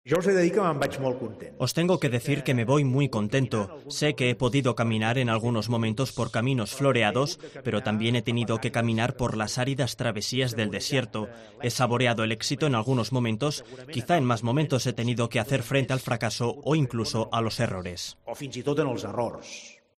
"Hay que saber sumar esto porque, si lo sabéis hacer, las municipales también las ganaremos y, más allá de ganar elecciones, haremos el mejor servicio del país", ha asegurado en su intervención durante el Consejo Nacional que celebra en el Auditorio AXA de Barcelona.